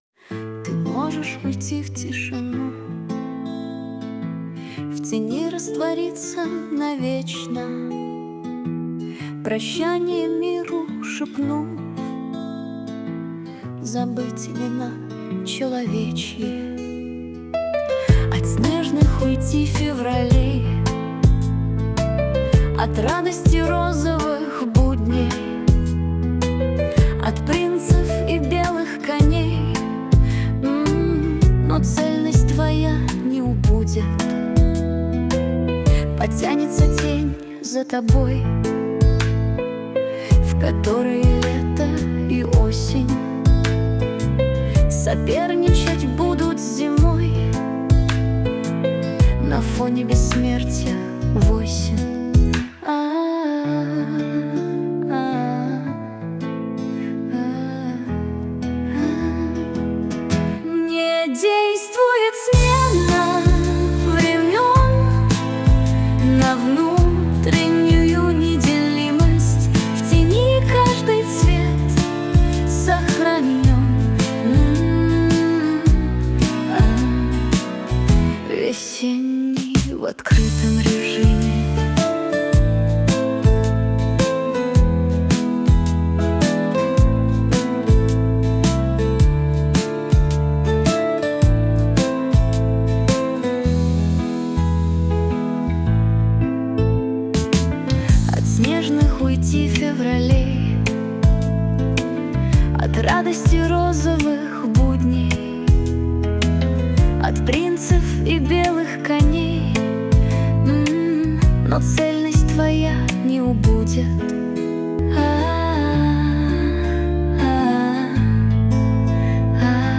mp3,3887k] Авторская песня